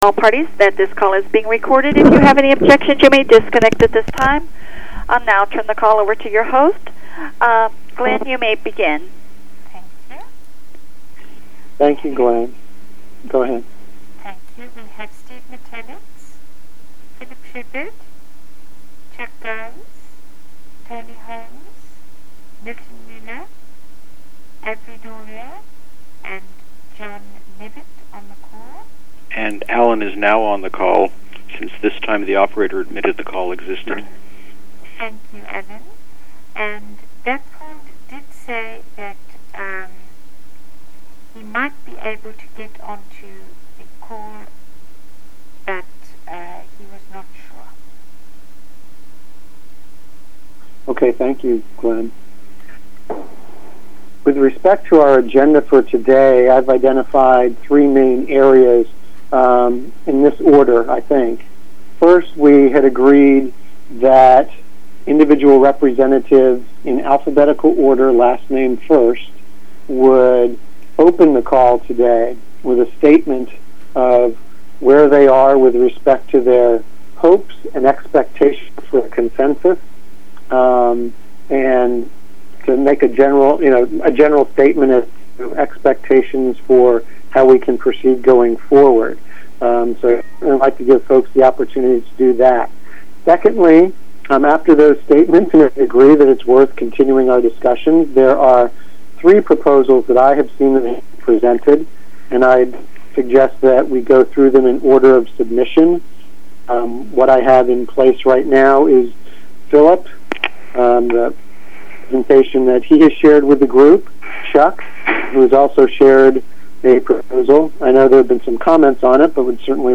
[gnso-consensus-wg] MP3 recording of the GNSO consensus call 17 July 2008